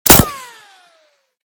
concrete01gr.ogg